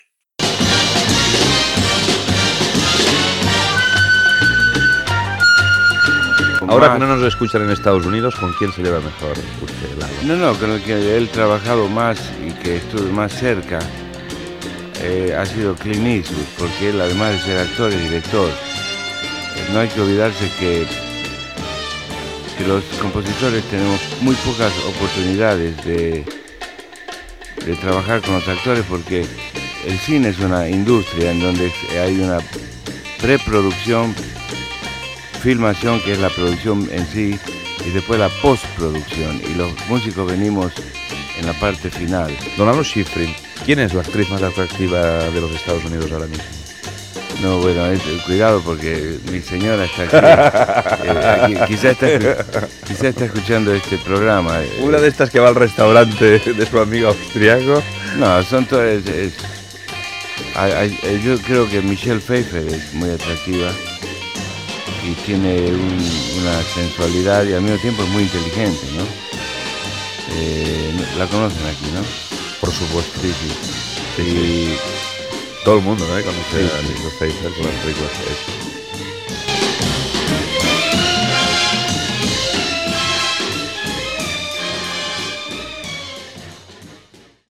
Entrevista al compositor Lalo Schiffrin.
Entreteniment
FM